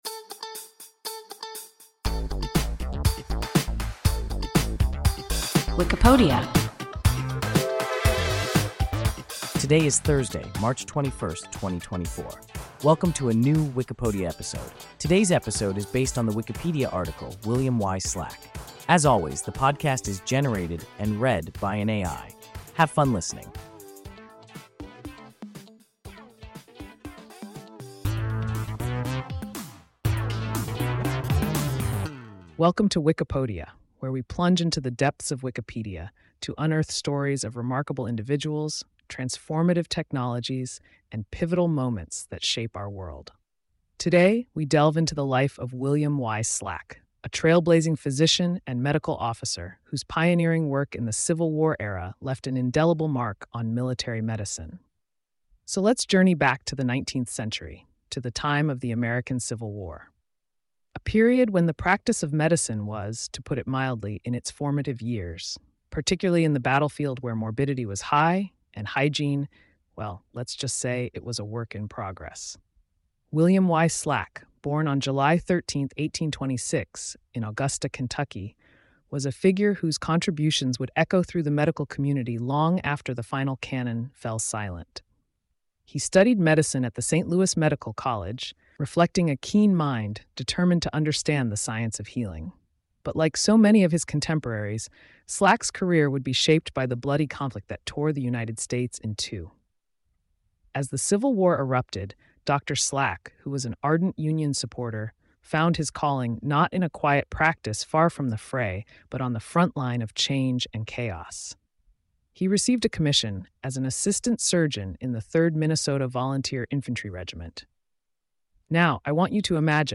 William Y. Slack – WIKIPODIA – ein KI Podcast